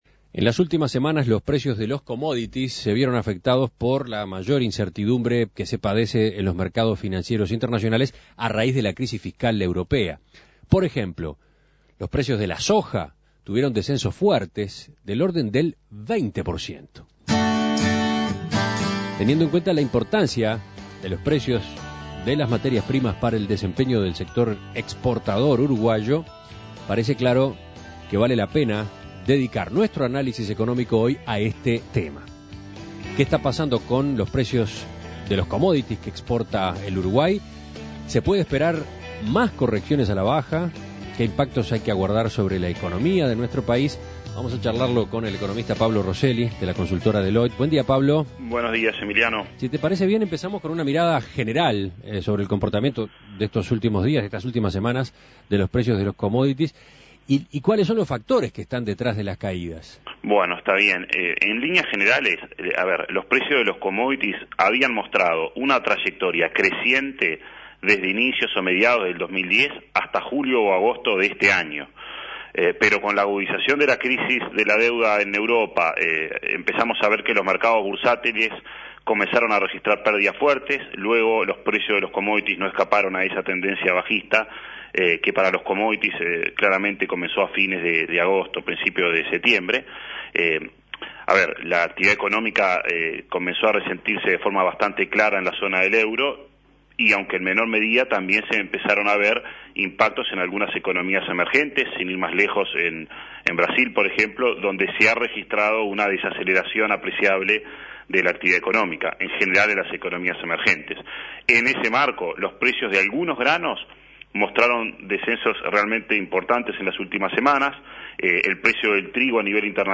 Análisis Económico Los precios de los commodities cayeron en las últimas semanas.